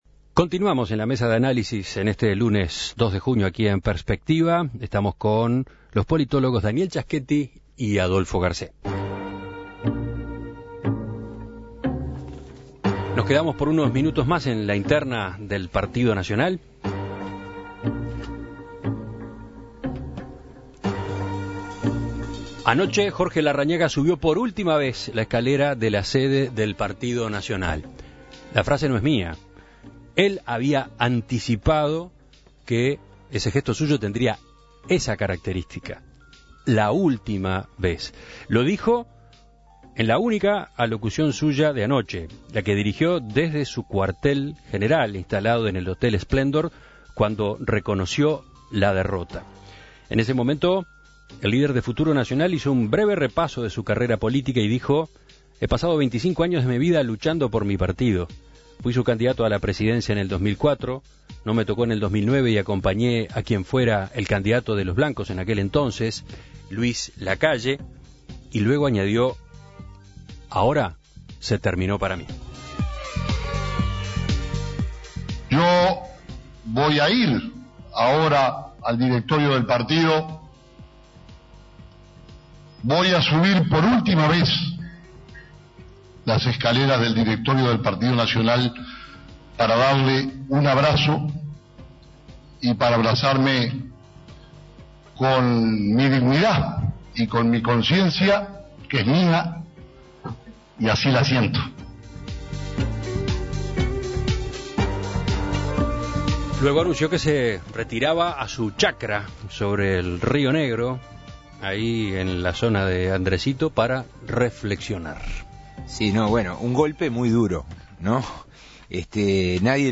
Comentarios de lo politólogos